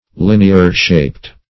Search Result for " linear-shaped" : The Collaborative International Dictionary of English v.0.48: Linear-shaped \Lin"e*ar-shaped`\ (l[i^]n"[-e]*[~e]r*sh[=a]pt`), a. Of a linear shape.